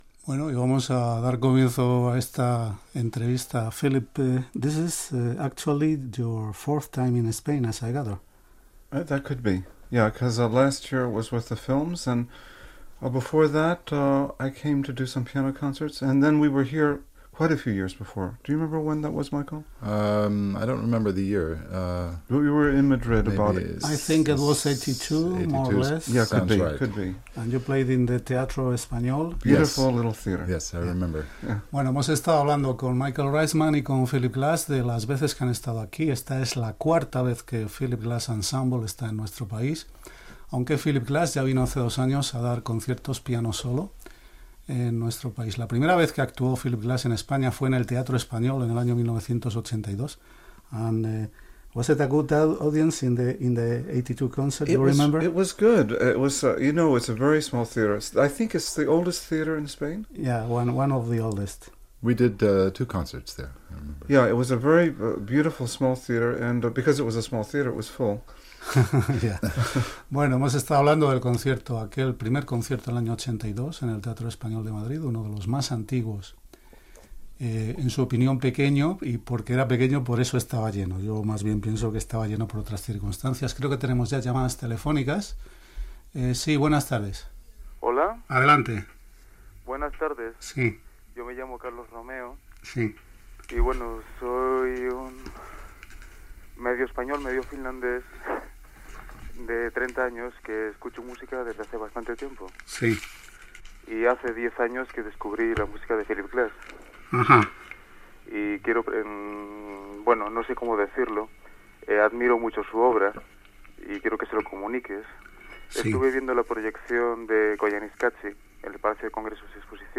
Entrevista al músic i compositor Philip Glass amb intervencions telefòniques de l'audiència